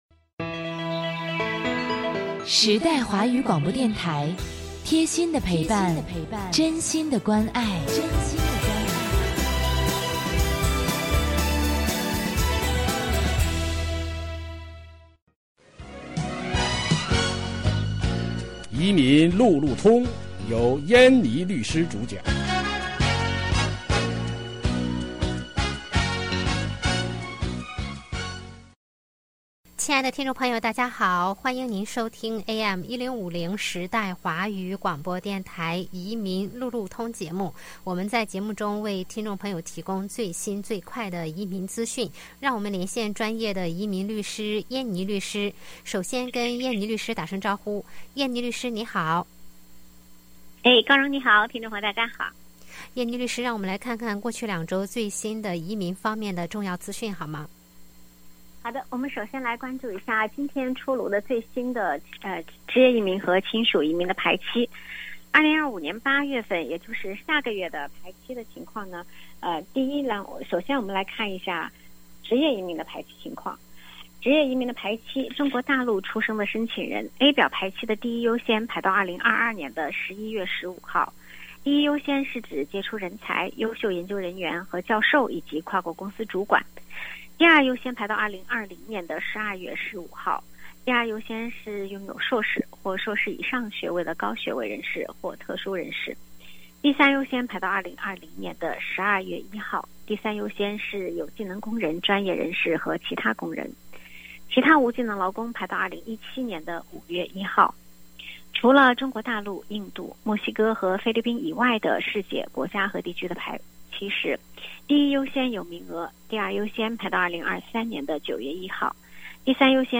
每月第二、第四个周一下午5点30分，AM1050时代华语广播电台现场直播，欢迎听众互动。